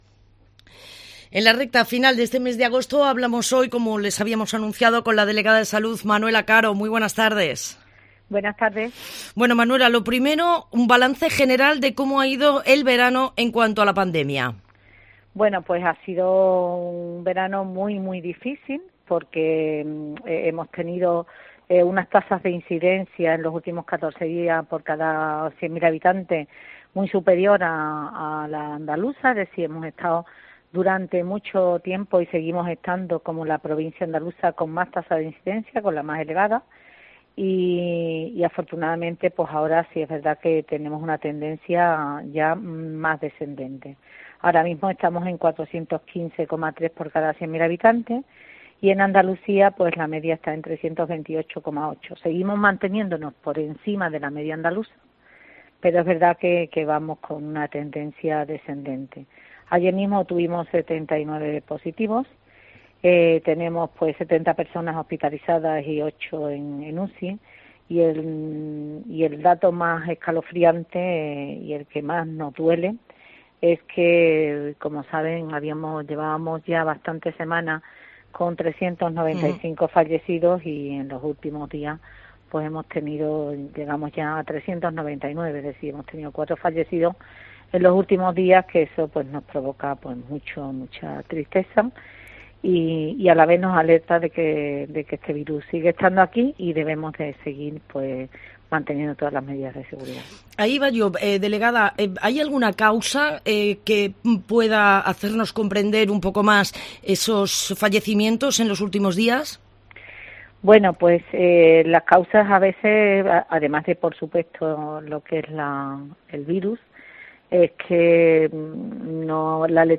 La delegada de Salud en Huelva, Manuela Caro que hoy ha participado en una entrevista en la Mañana en Huelva ha confirmado que habrá tercera dosis de la vacuna.